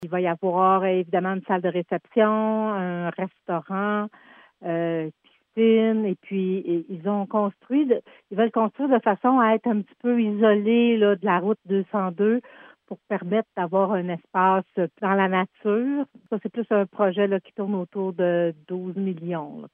La mairesse de Cowansville, Sylvie Beauregard donne plus de détails.